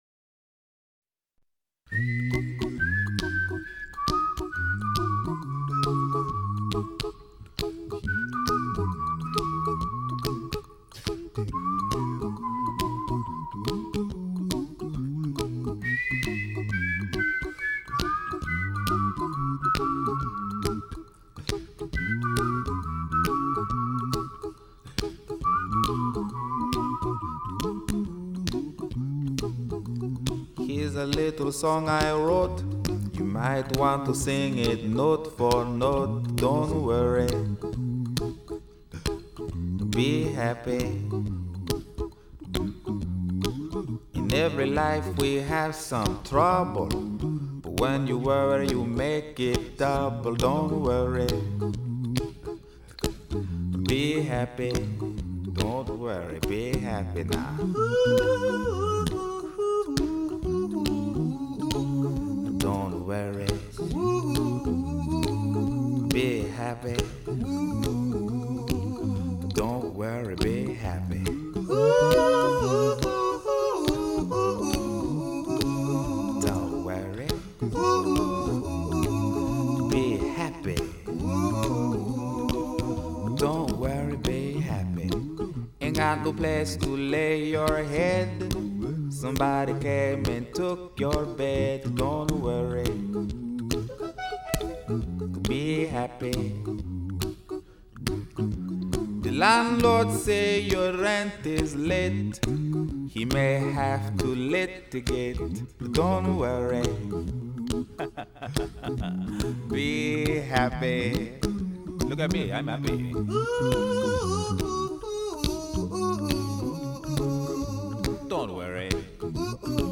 The song is kinda fun, whimsical.